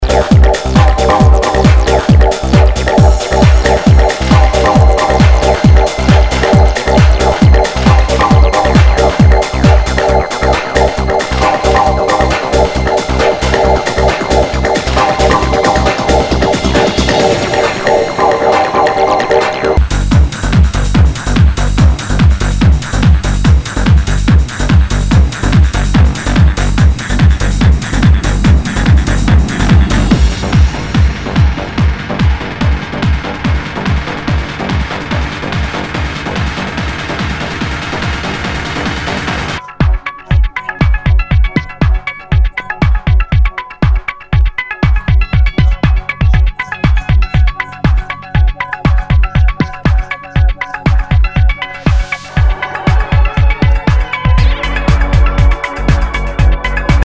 HOUSE/TECHNO/ELECTRO
ナイス！トランス・テクノ！